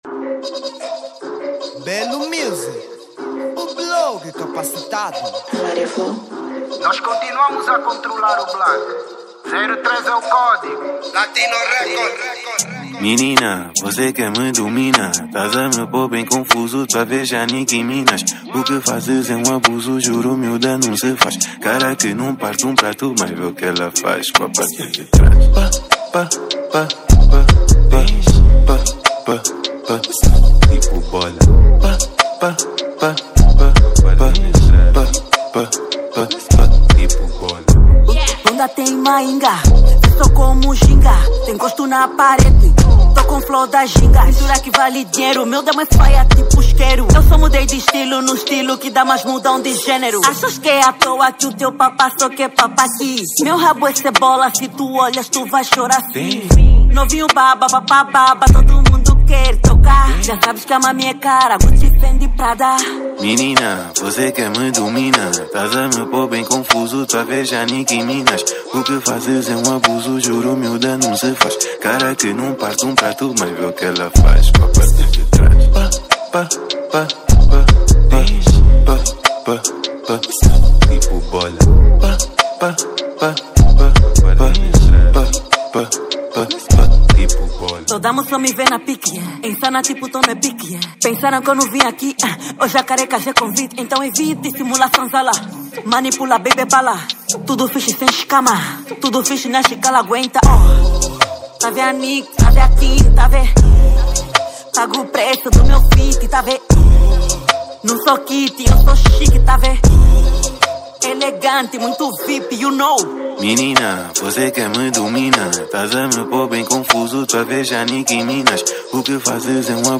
Género: Trap